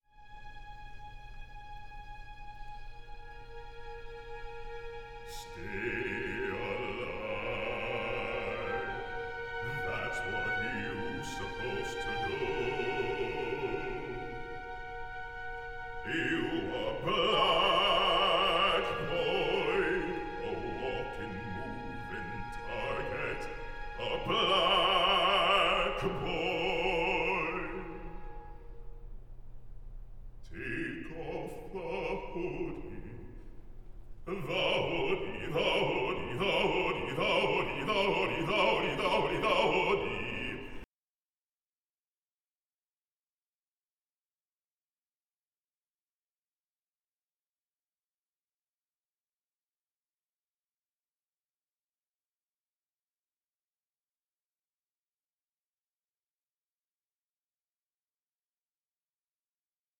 a contemporary opera on racial injustice in the US today